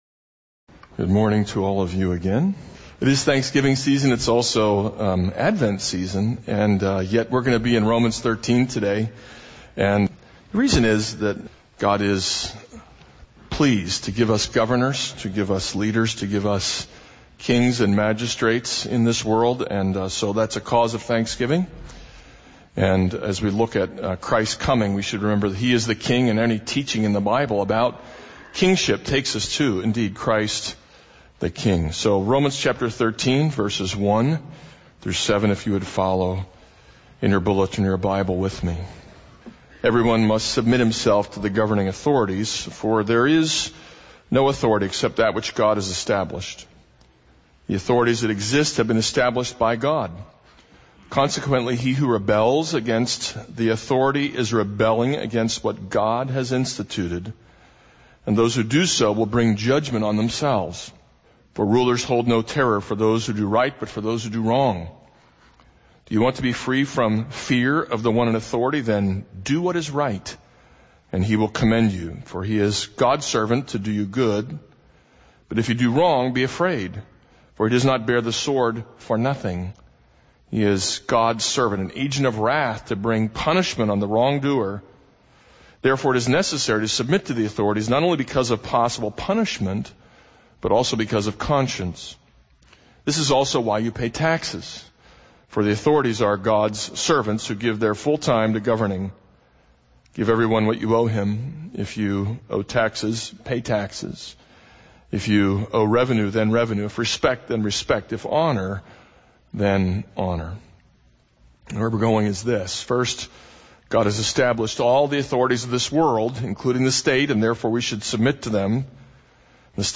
This is a sermon on Romans 13:1-7.